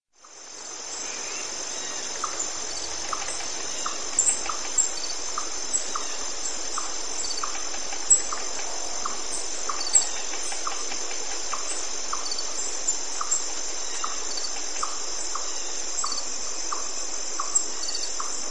90 minuti di suoni naturali professionalmente registrati.
Attenzione: Questi suoni sono talmente calmanti da poterti far cadere in uno stato profondamente rilassato.
La Foresta (3 files. Durata: 30 minuti)
Uccelli e grilli cantano, il vento soffia tra gli alberi e l'acqua scorre in un ruscello vicino.
forest1sample.mp3